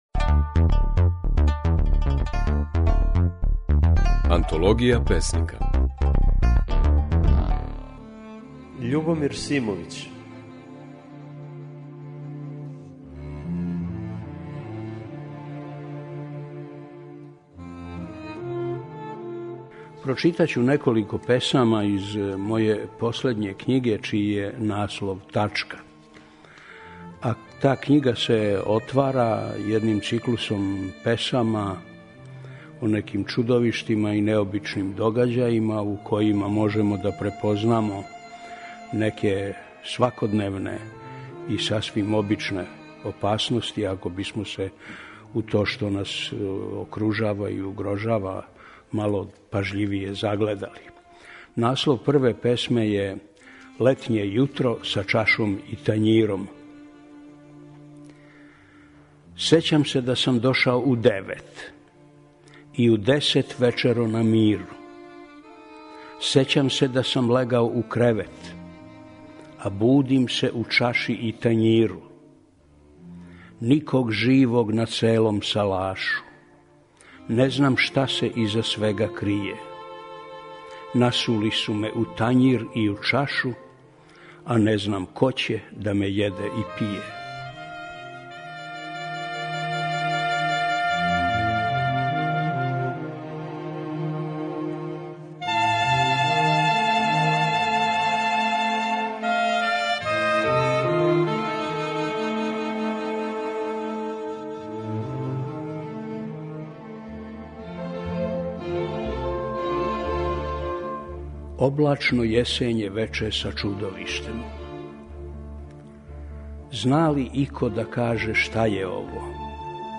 Можете чути како своје стихове говори песник, драмски писац и есејиста Љубомир Симовић (Ужице, 1935).